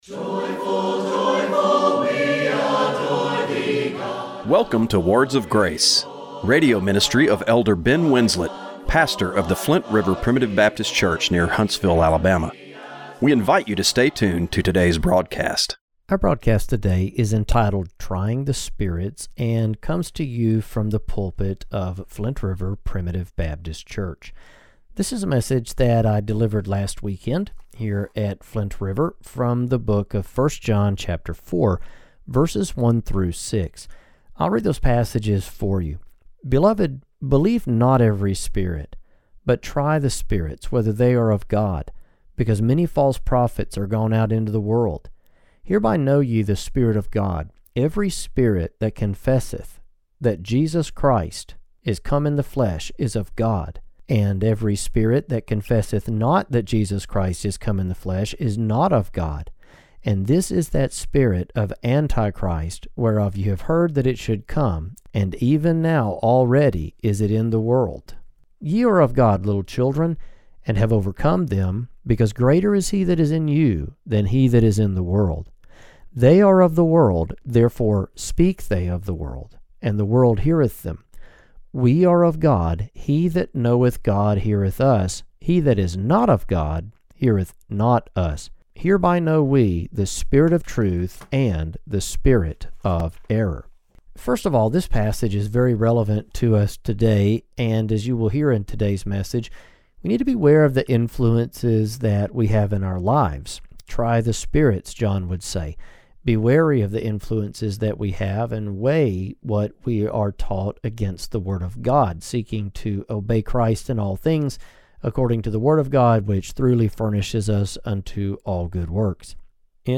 Radio broadcast for November 24, 2024